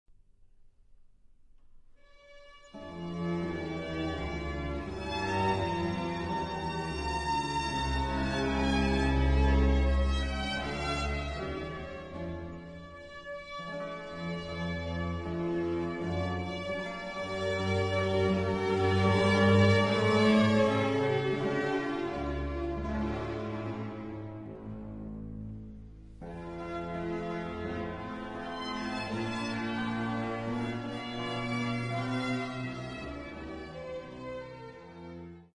Symphony in D major